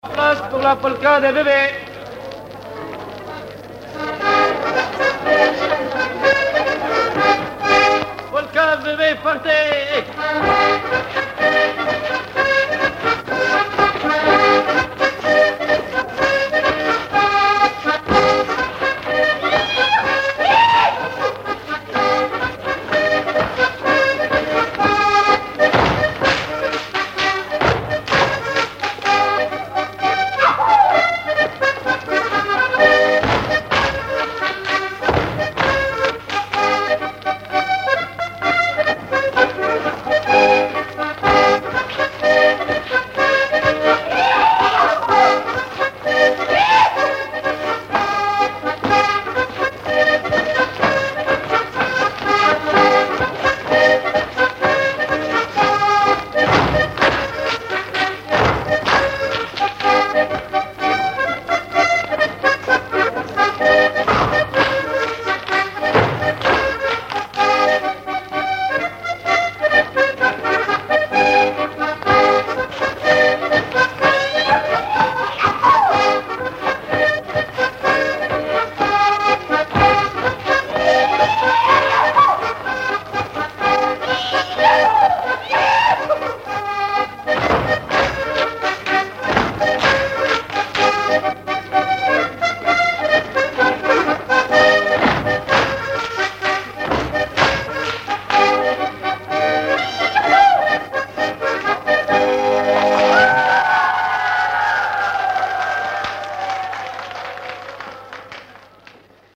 danse : polka des bébés ou badoise
Pièce musicale inédite